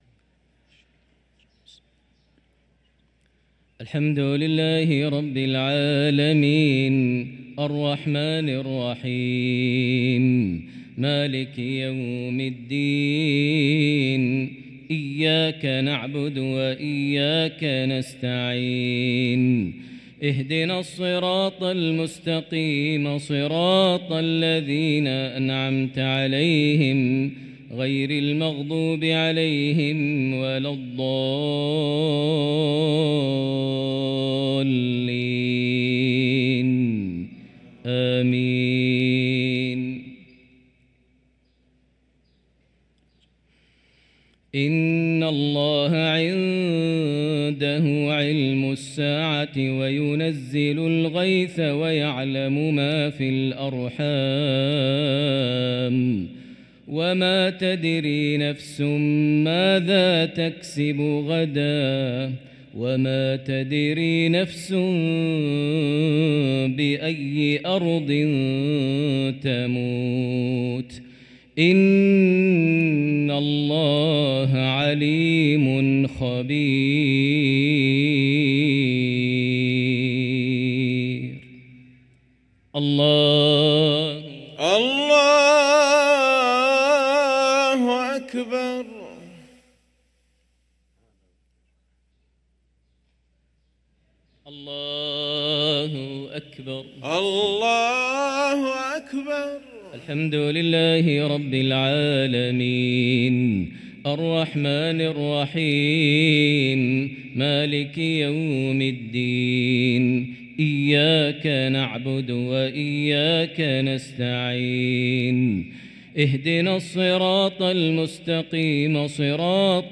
صلاة المغرب للقارئ ماهر المعيقلي 19 ربيع الآخر 1445 هـ
تِلَاوَات الْحَرَمَيْن .